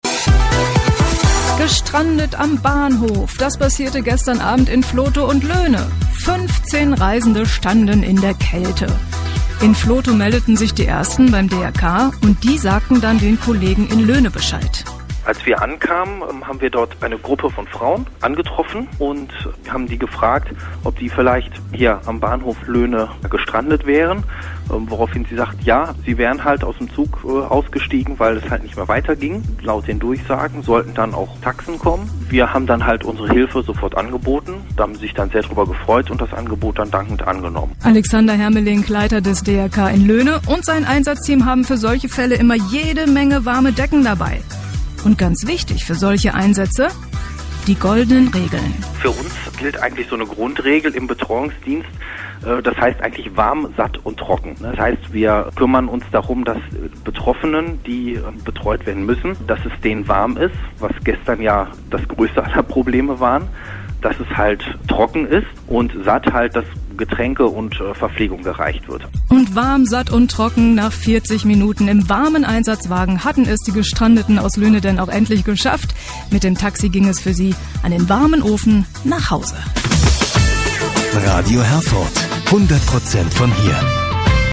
Für Interessierte gibt hier den Beitrag von Radio Herford über unseren Betreuungseinsatz zum nachhören: